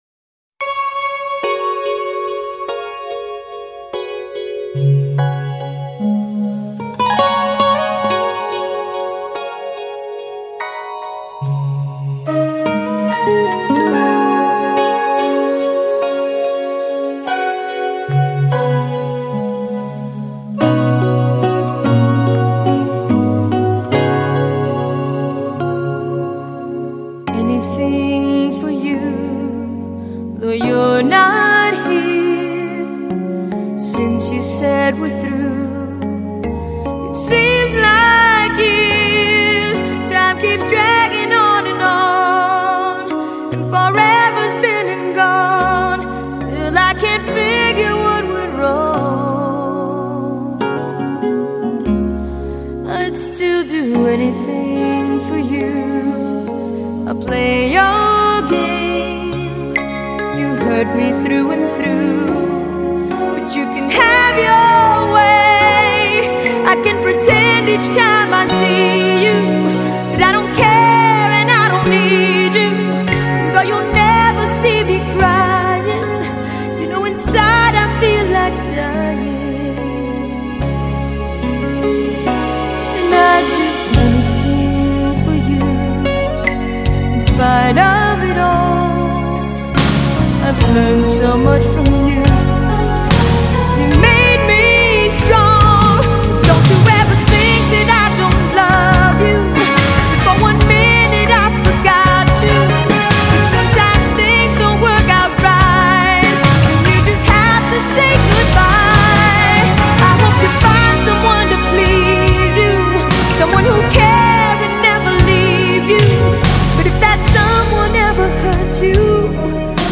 Pop
추억의 러브 팝송 모음